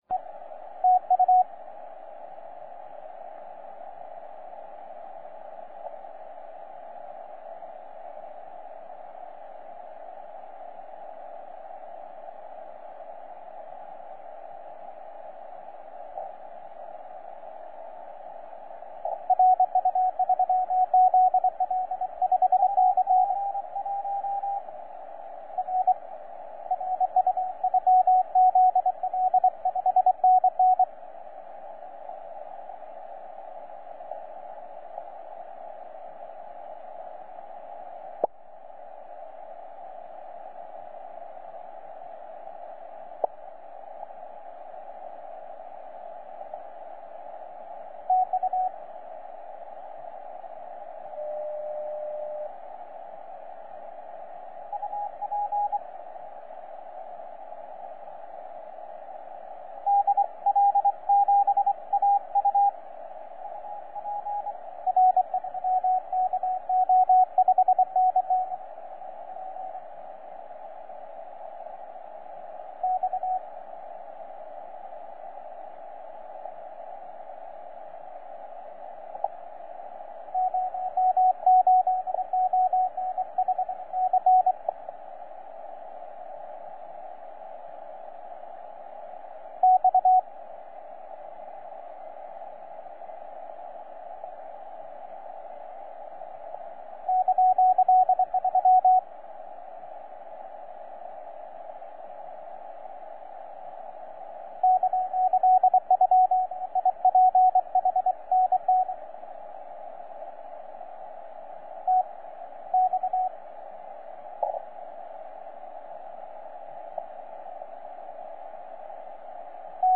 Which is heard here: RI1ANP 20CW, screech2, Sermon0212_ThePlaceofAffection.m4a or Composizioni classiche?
RI1ANP 20CW